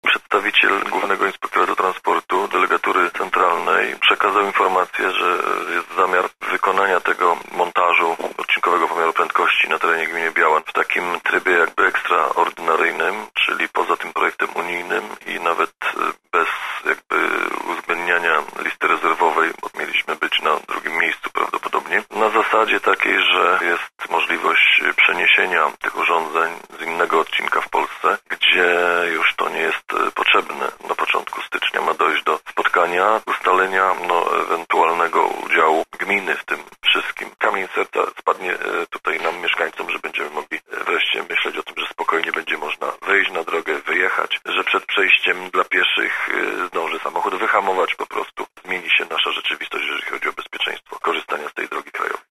Mówi Aleksander Owczarek, wójt gminy Biała.